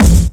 GS Phat Kicks 009.wav